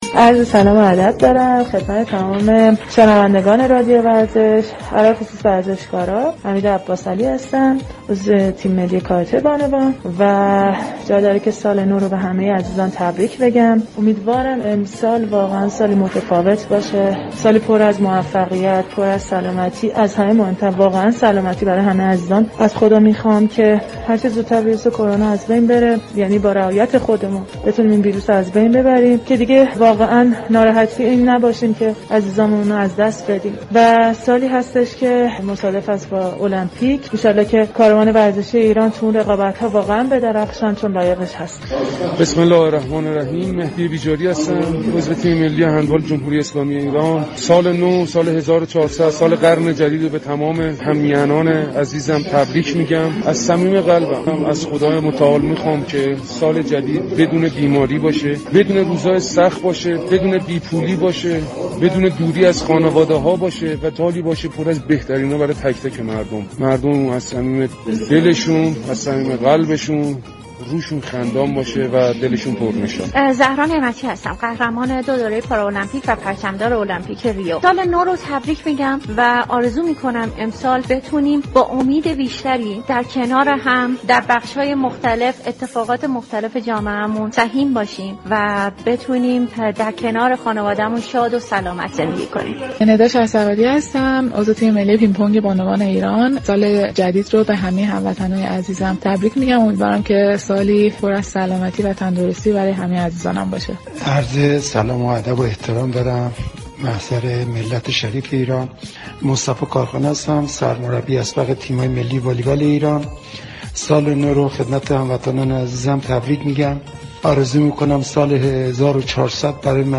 شبكه رادیویی ورزش در تحویل سال 1400 ویژه برنامه